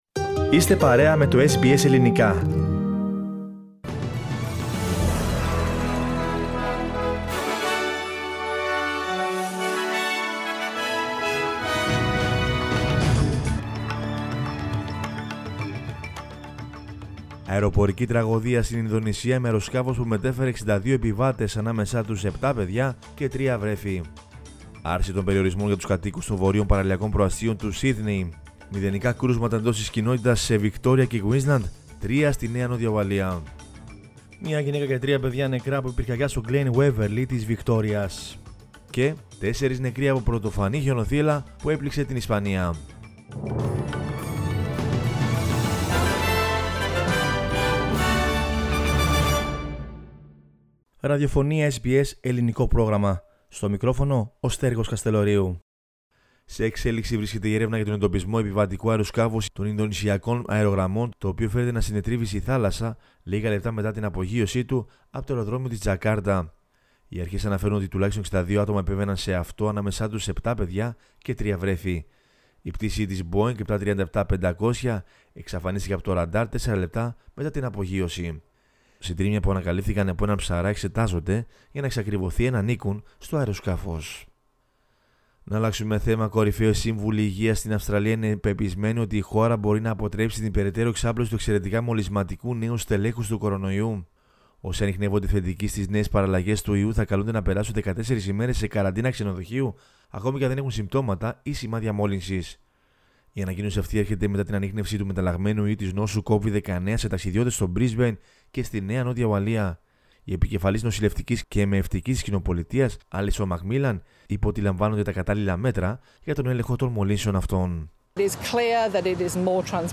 News in Greek from Australia, Greece, Cyprus and the world is the news bulletin of Sunday 10 January 2021.